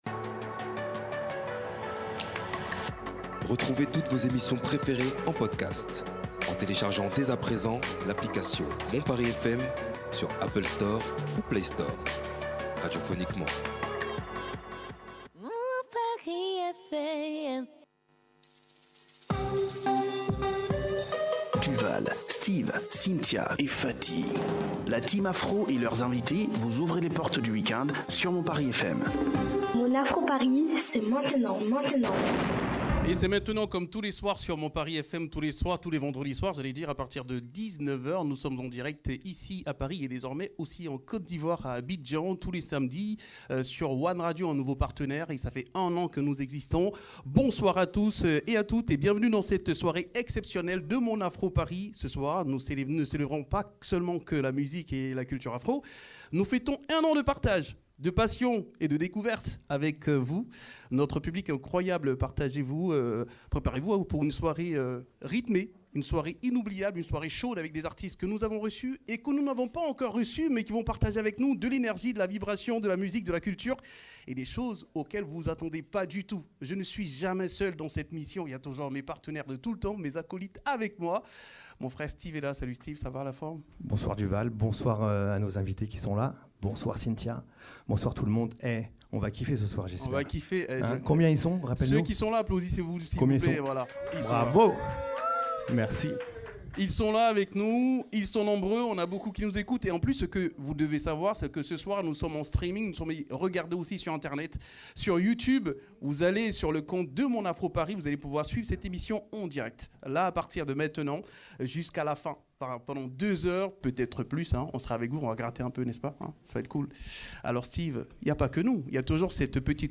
À cette occasion, une véritable brochette d’artistes viendra enflammer la scène et partager ce moment unique avec vous, nos fidèles auditeurs.
Au programme : de la musique, des performances inédites, des surprises et surtout une ambiance festive et conviviale, à l’image de MON AFRO PARIS.